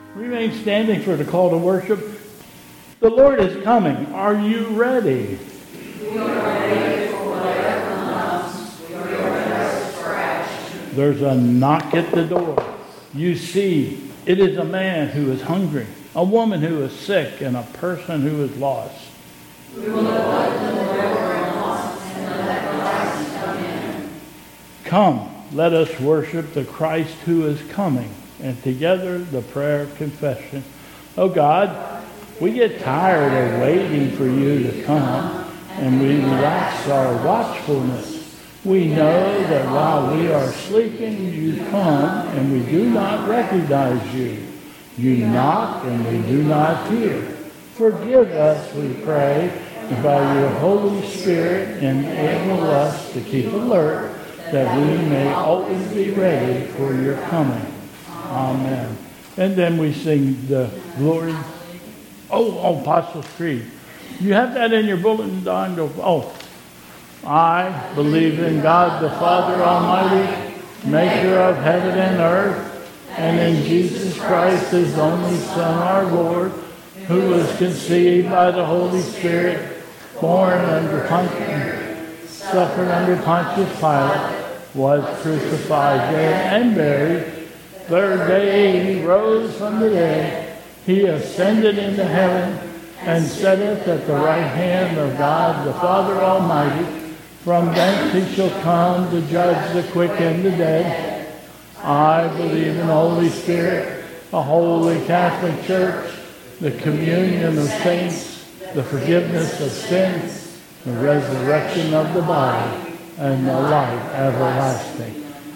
2022 Bethel Covid Time Service
Call to Worship...